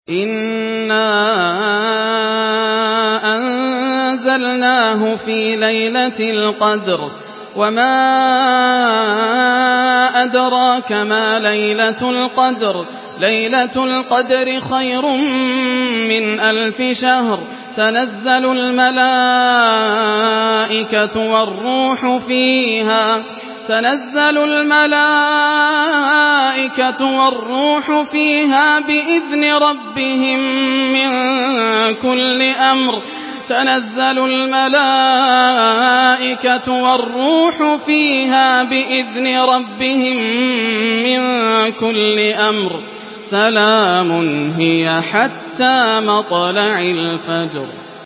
Surah Al-Qadr MP3 in the Voice of Yasser Al Dosari in Hafs Narration
Murattal Hafs An Asim